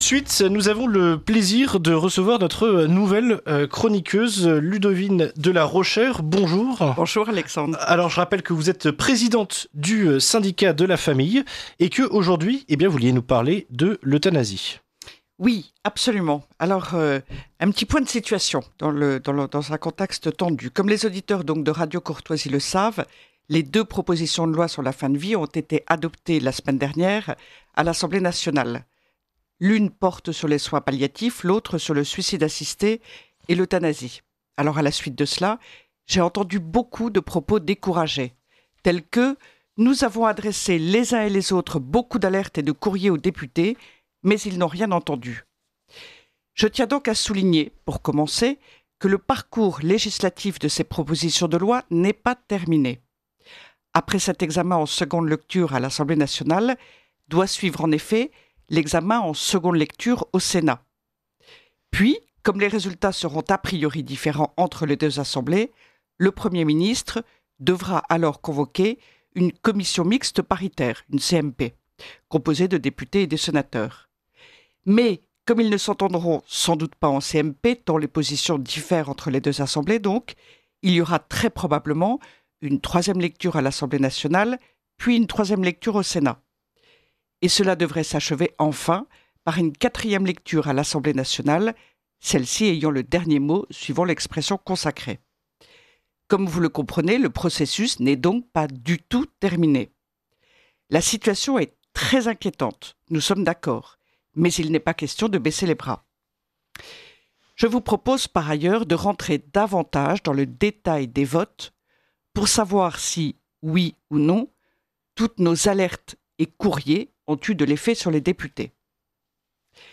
diffusée chaque jeudi dans la matinale de Radio Courtoisie